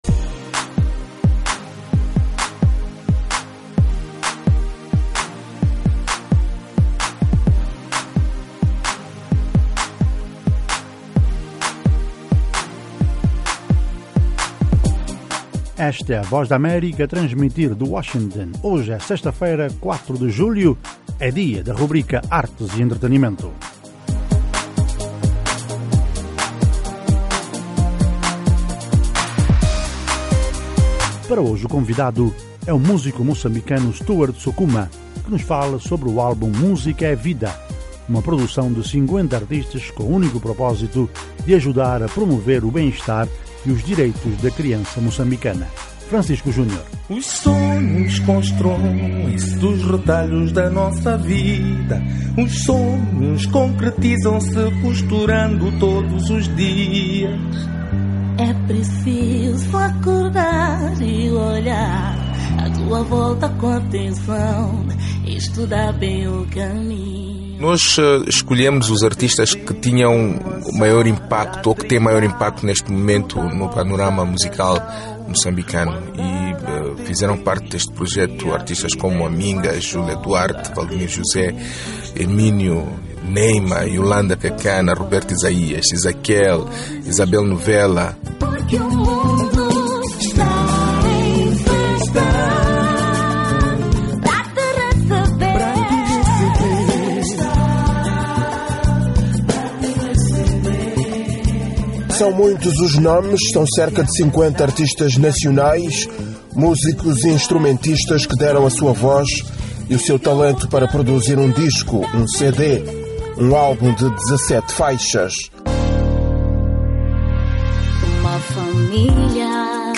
Artes e Entretenimento - entrevista com Stewart Sukuma